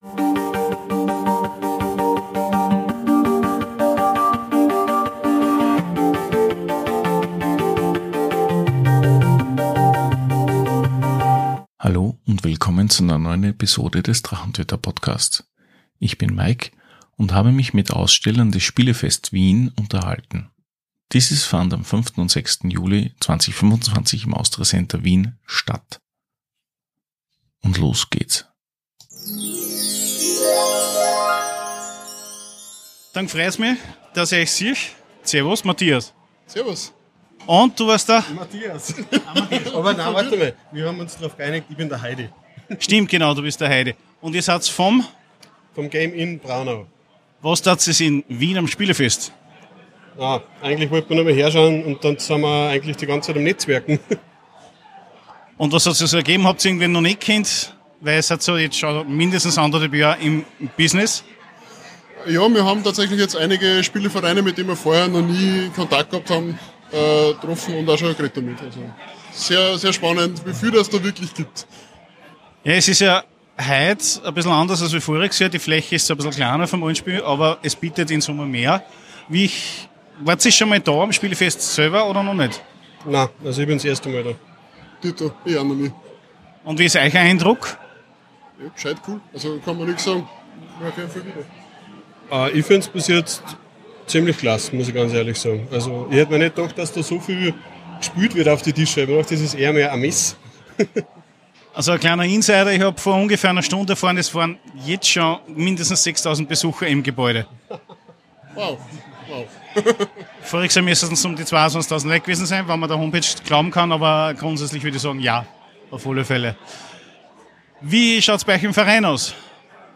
Episode 181 - Spielefest Wien 2025 - Die Interviews ~ Der Drachentöter Podcast